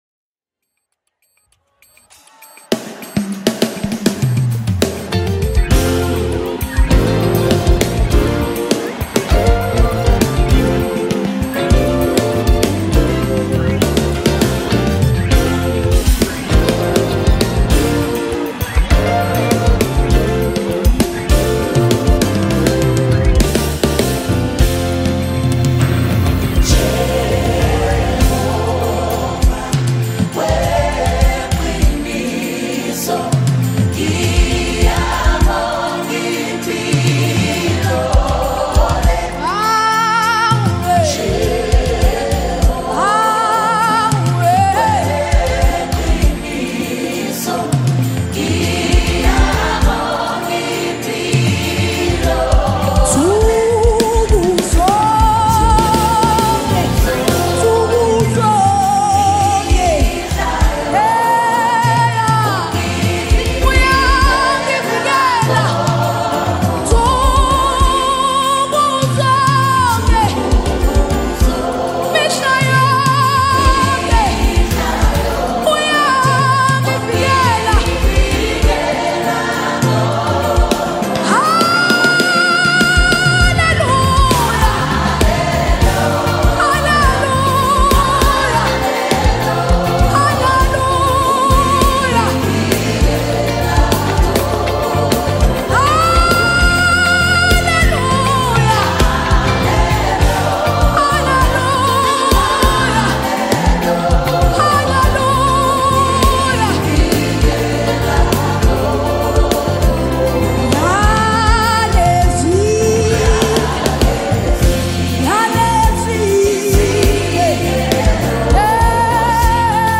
Home » Gospel
South African singer